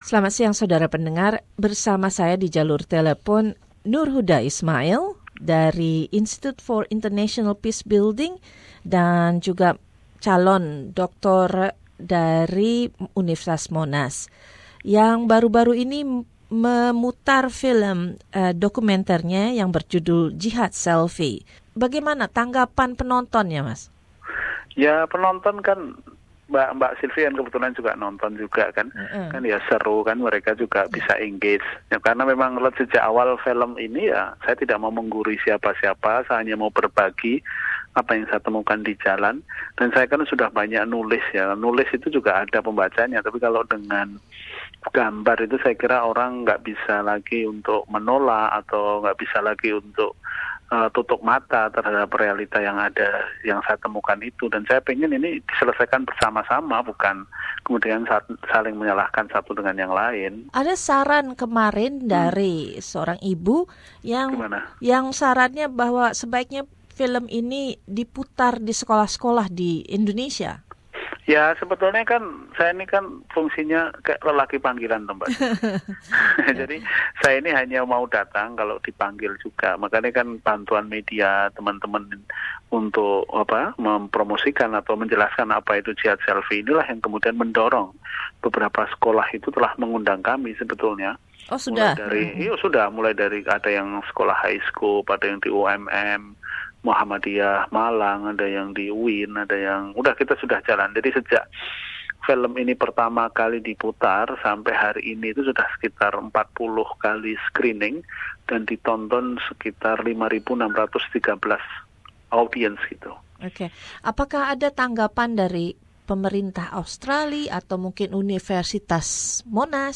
Dalam wawancara ini, Ia menjelaskan tujuan dari film dokumenter ini.